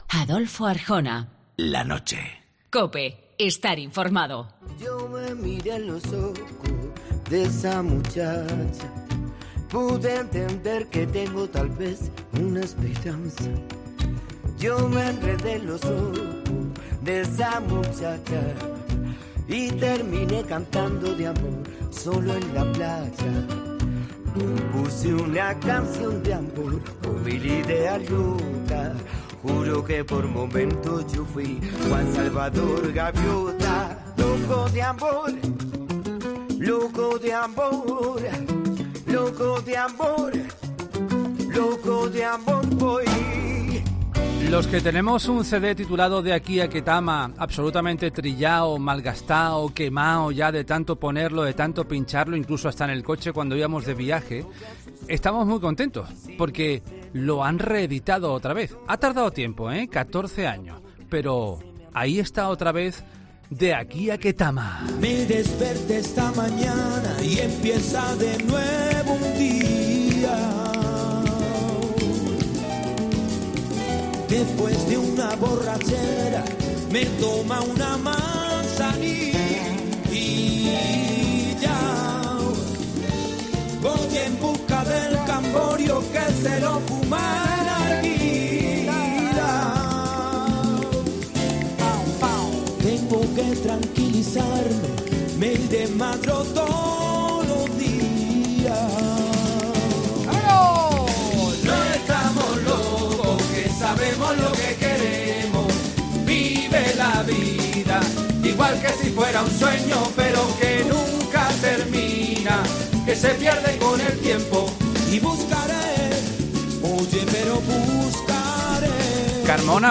Antonio Carmona, de Ketama, ha pasado por los micrófonos de La Noche de COPE, para contar qué esperan y cómo han vivido esta nueva aventura musical.